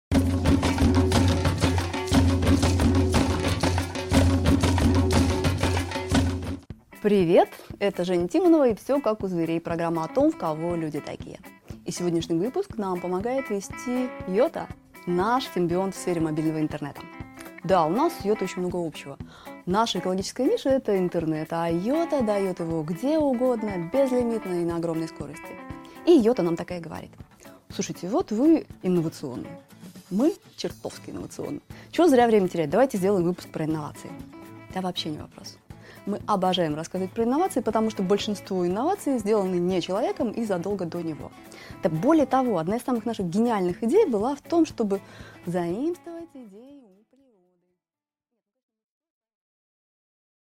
Аудиокнига Изобретено природой, запатентовано человеком | Библиотека аудиокниг